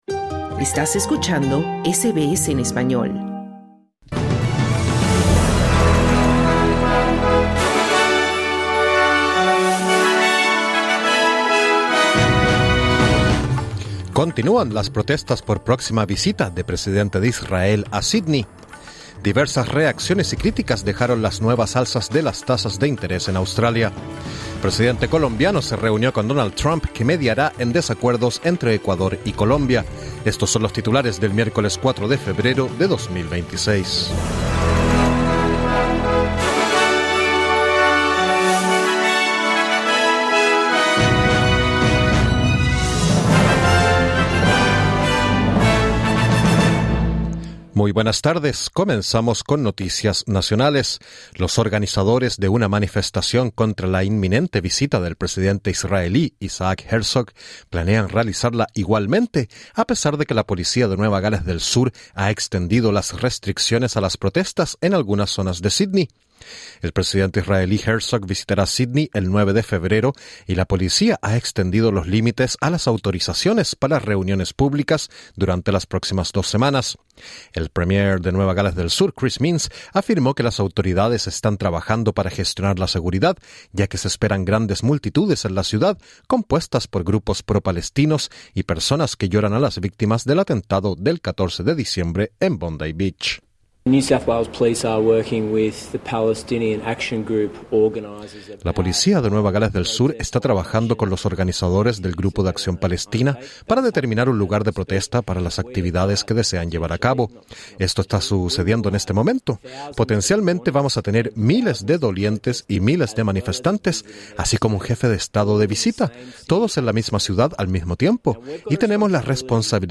Boletín de noticias 04/02/26: Se están organizando protestas en Sídney por visita de presidente de Israel, a pesar de restricciones. Diversas reacciones y críticas dejaron las nuevas subidas en las tasas de interés. Presidente colombiano Gustavo Petro se reunió con Donald Trump que prometió mediar en tensiones diplomáticas entre Ecuador y Colombia.